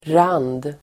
Uttal: [ran:d]